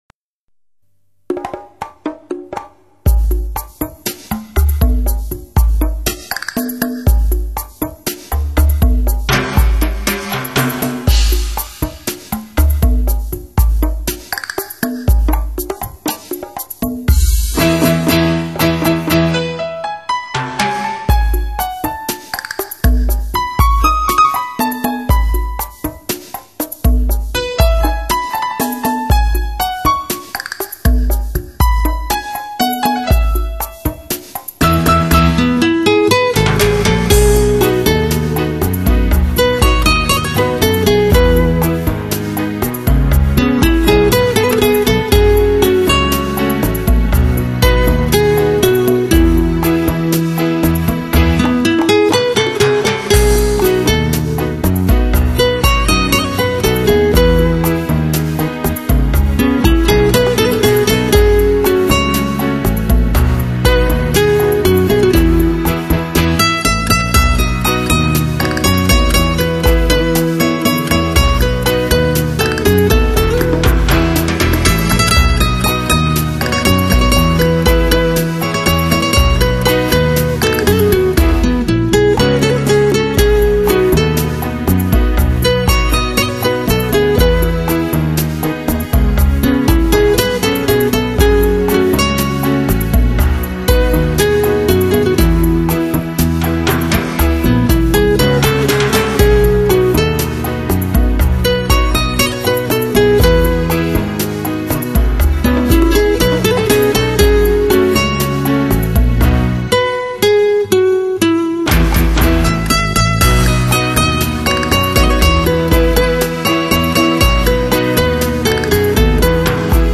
浪漫吉他